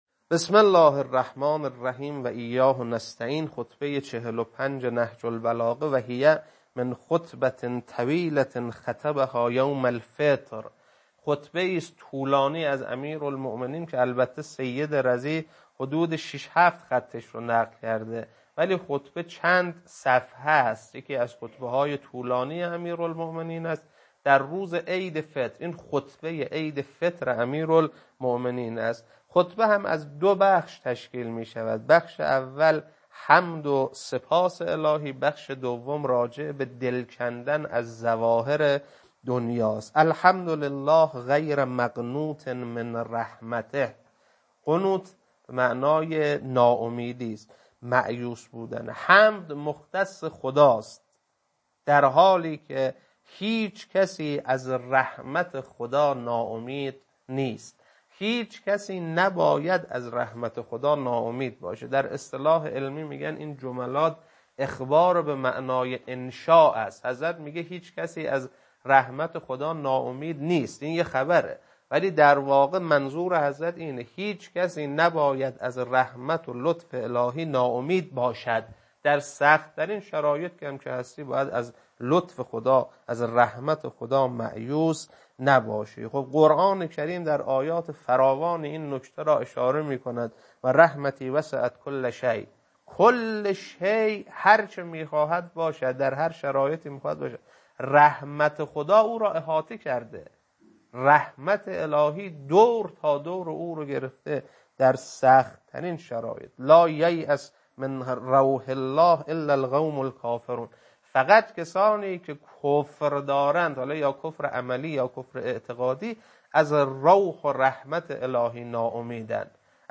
خطبه-45.mp3